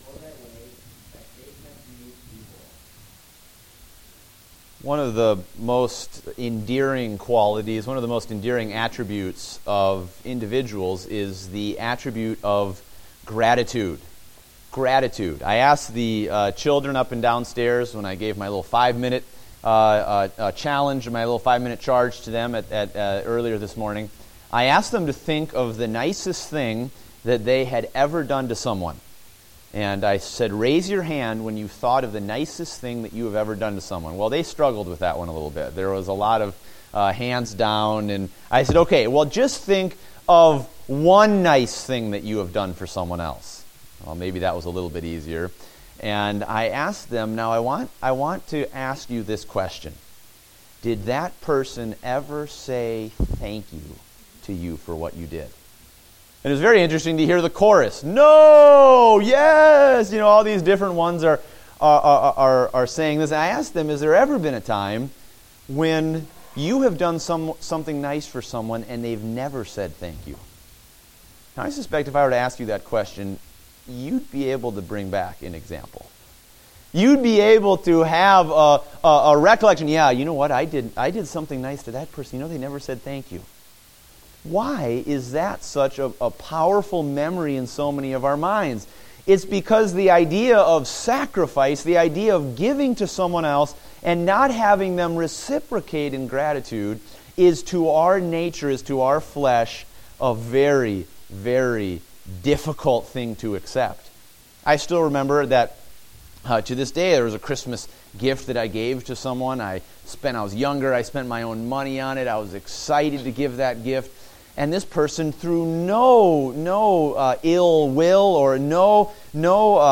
Date: February 14, 2016 (Adult Sunday School)